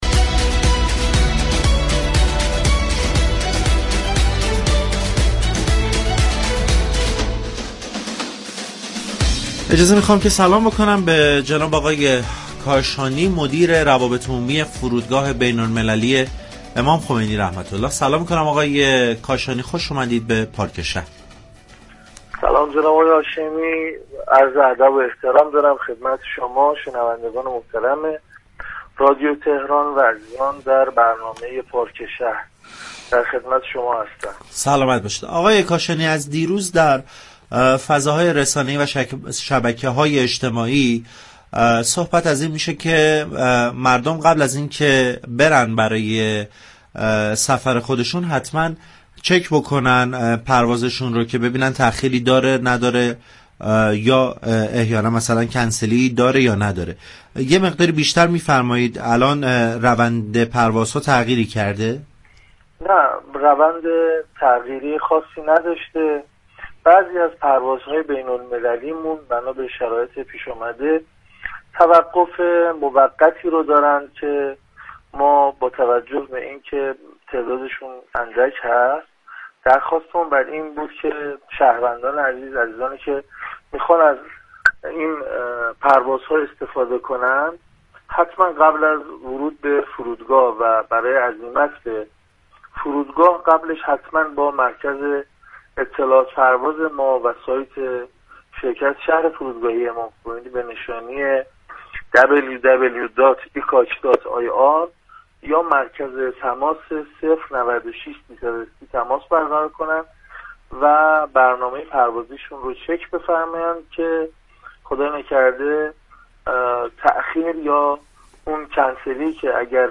در گفتگو با پارك شهر